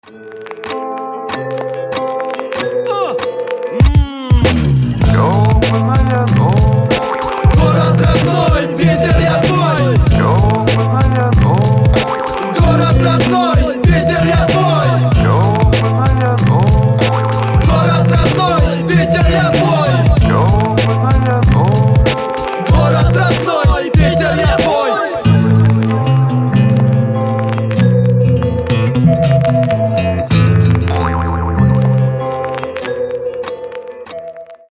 • Качество: 320, Stereo
Хип-хоп
пацанские